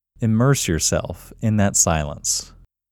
QUIETNESS Male English 13
The-Quietness-Technique-Male-English-13.mp3